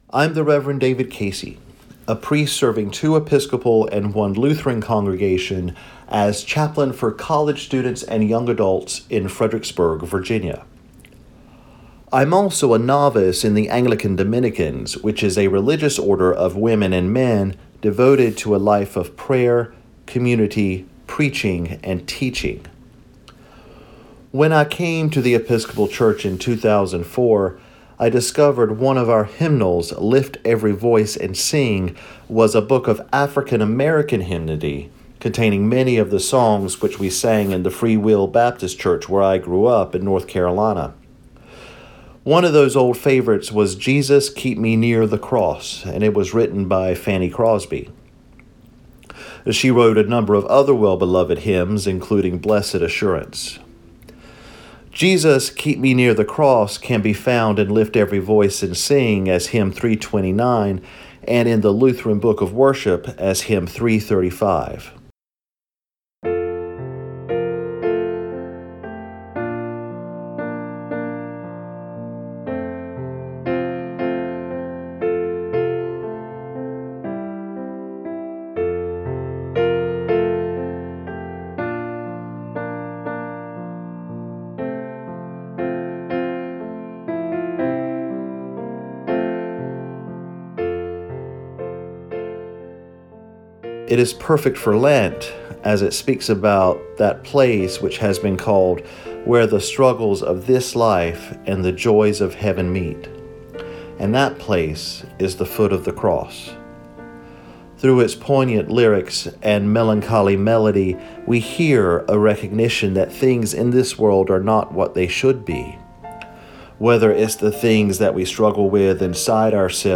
Songs in the Desert is an audio Lenten devotional exploring Christian hymns.